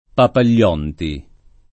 [ papal’l’ 0 nti ]